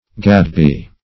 gadbee - definition of gadbee - synonyms, pronunciation, spelling from Free Dictionary Search Result for " gadbee" : The Collaborative International Dictionary of English v.0.48: Gadbee \Gad"bee`\, n. (Zool.)